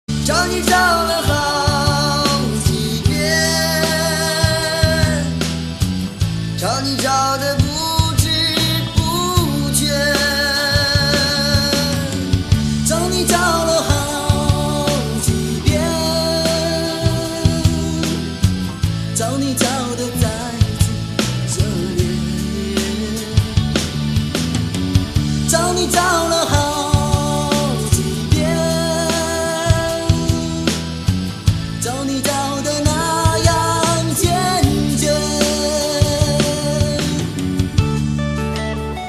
M4R铃声, MP3铃声, 华语歌曲 52 首发日期：2018-05-14 12:08 星期一